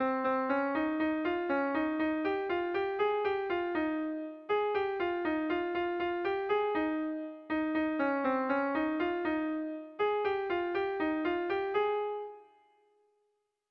Kontakizunezkoa
Kopla handia
ABD